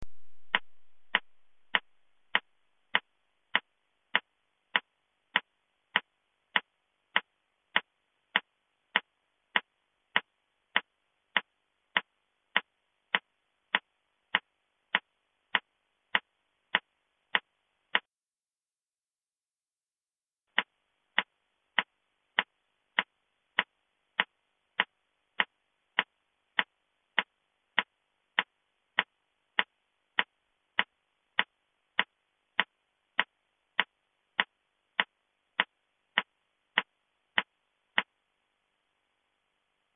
CPR（心肺蘇生）リズム
胸骨圧迫30回(100回/分ﾍﾟｰｽ)＋(2秒間人工呼吸はﾊﾟｽOK)　→